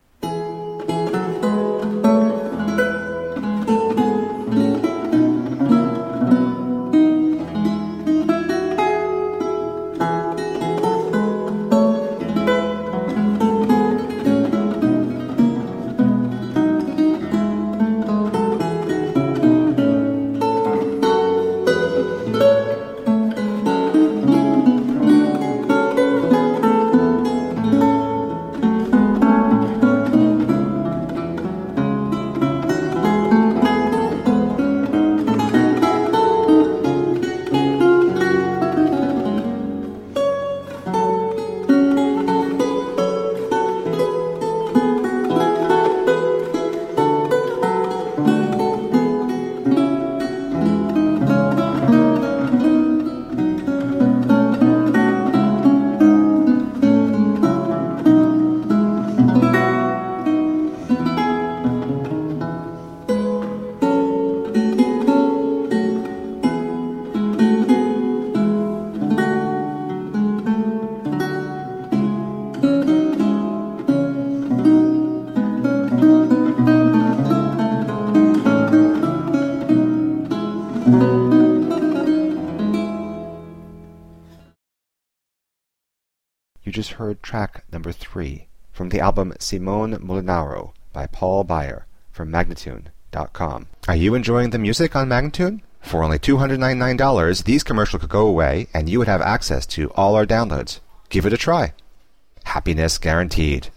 Solo lute of the italian renaissance..